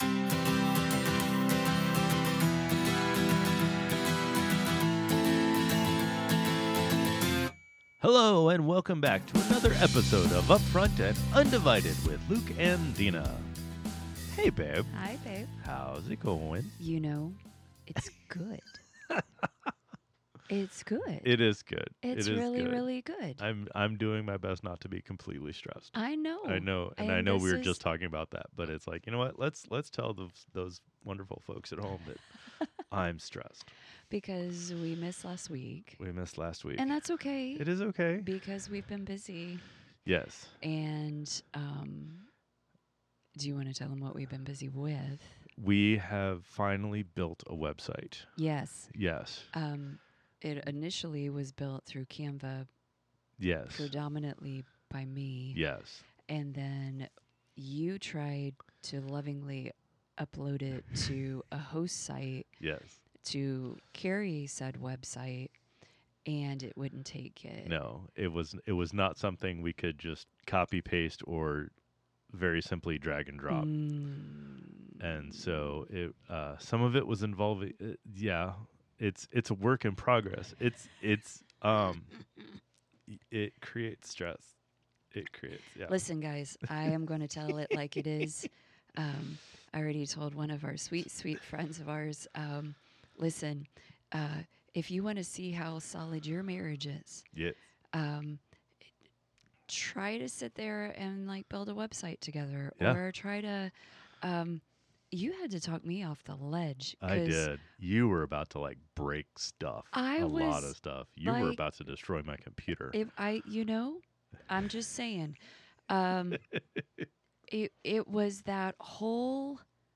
Real conversations with a real couple. In our podcast we will talk about and take on a variety of topics including: - Relationships/Marriage - Courting/Dating - Honor - Social Situations - Life - God and Christianity - Sex - Children - and any other random thing we might think of.